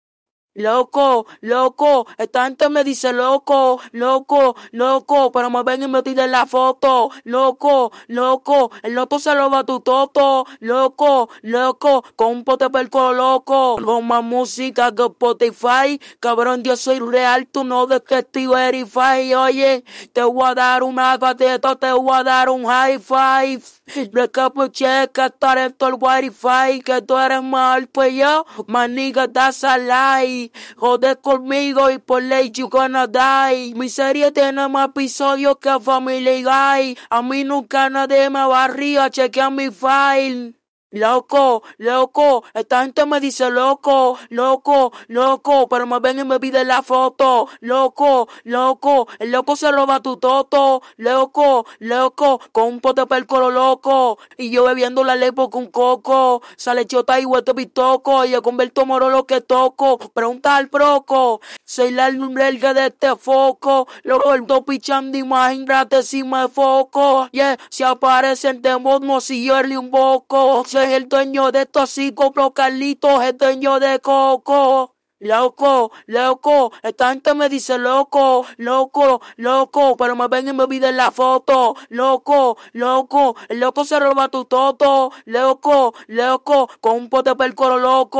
Diablo-A-(Vocals)-Loco.wav